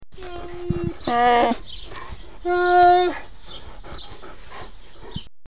На этой странице собраны звуки полярного медведя – мощные рыки, ворчание и шаги по снегу.
Звук голоса крошечного белого медвежонка